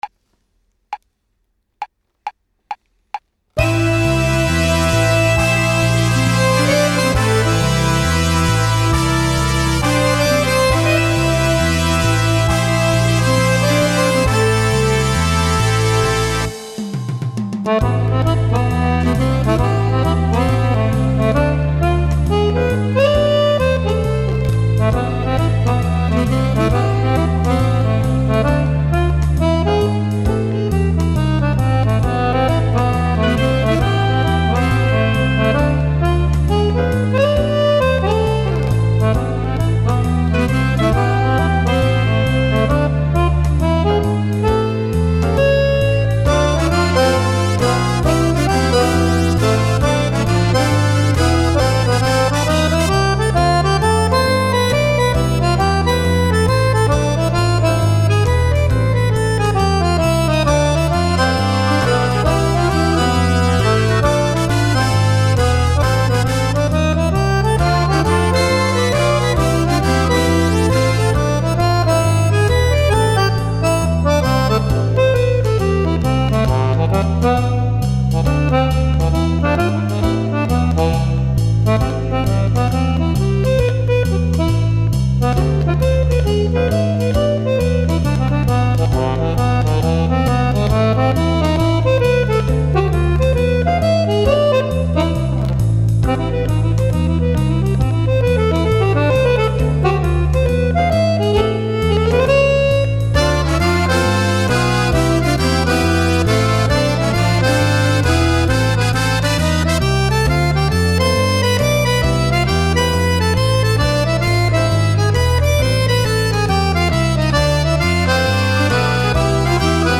Per Fisarmonica